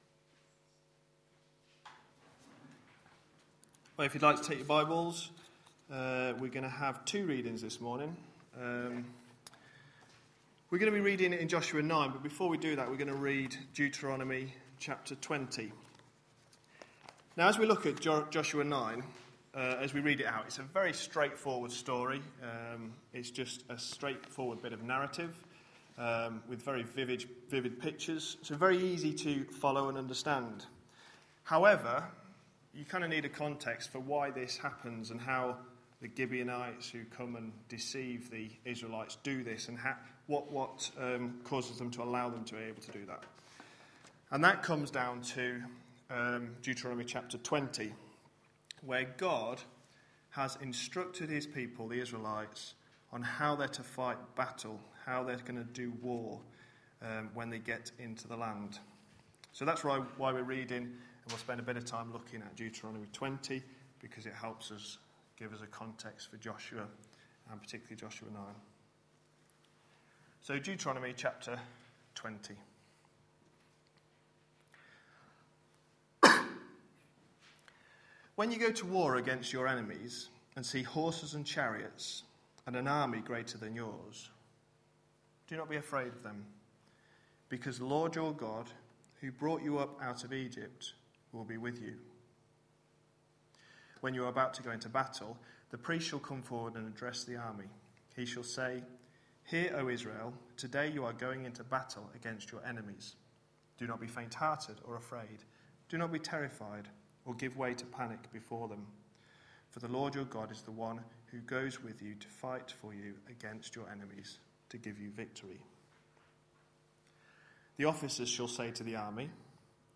A sermon preached on 13th May, 2012, as part of our Entering God's Rest series.